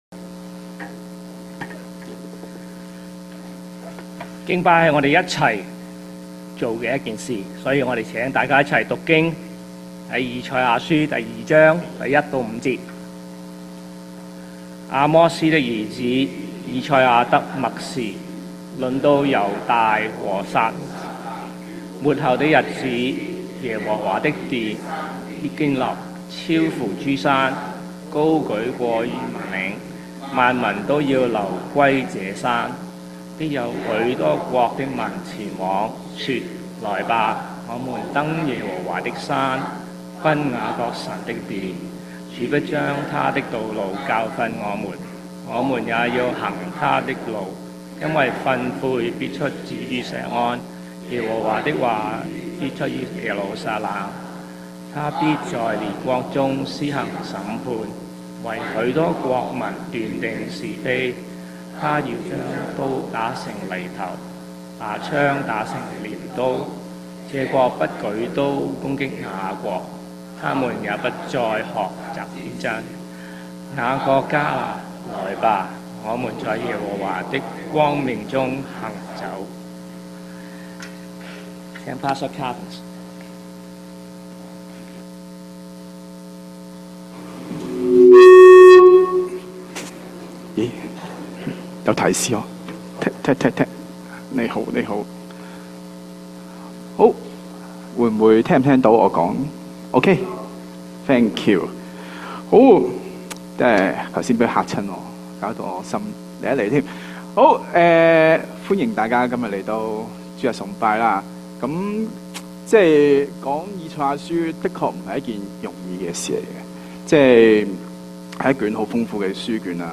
粵語堂主日崇拜-《走在光明中》-《以賽亞書-2-1-5-節》.mp3